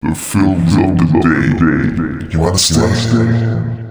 035 male.wav